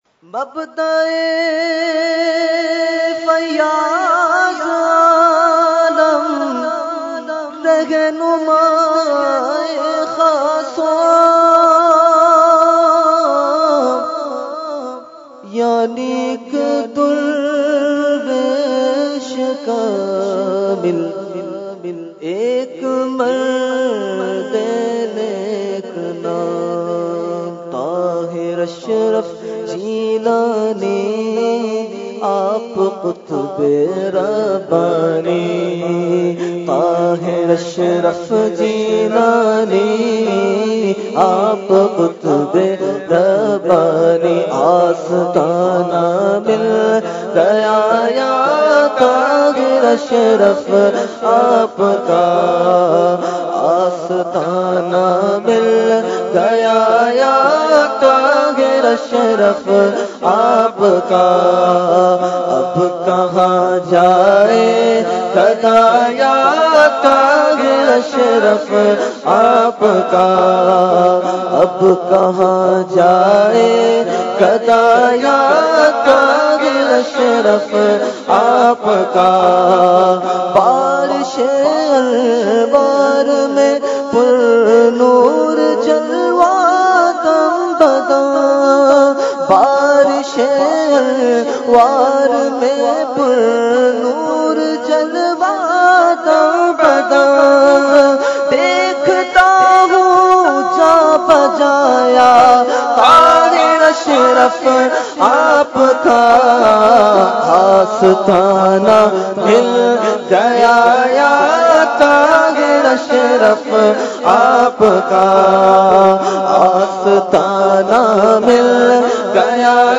Category : Manqabat | Language : UrduEvent : Urs Qutbe Rabbani 2019